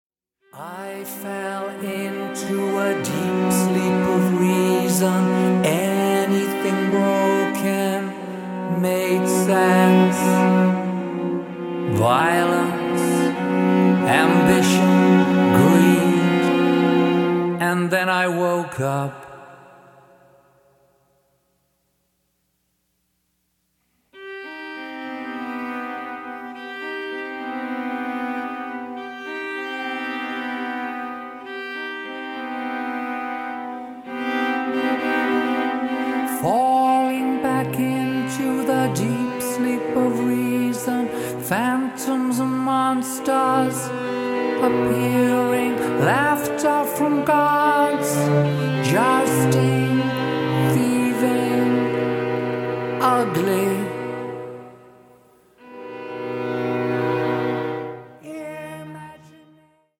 ピアノ
ボーカル